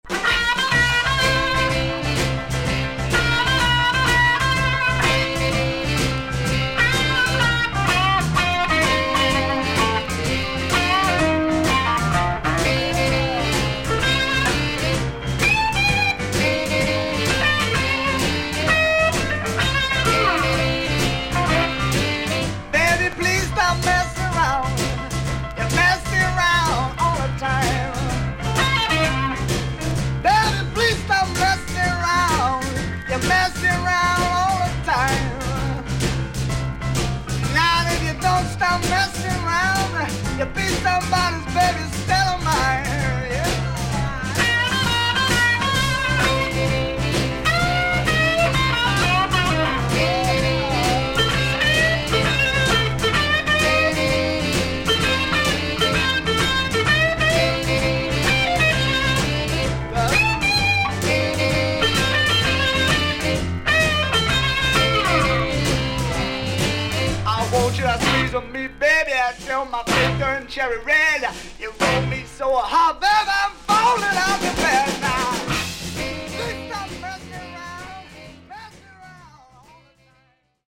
少々軽いパチノイズの箇所あり。少々サーフィス・ノイズあり。クリアな音です。
ブルース・ロック名盤。